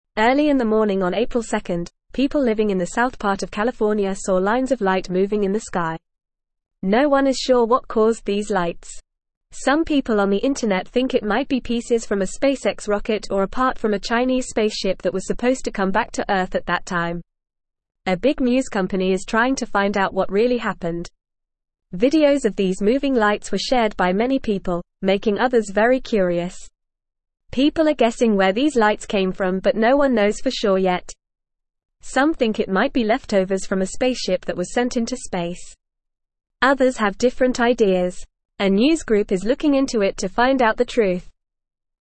Fast
English-Newsroom-Beginner-FAST-Reading-Mysterious-Lights-in-California-Sky-What-Happened.mp3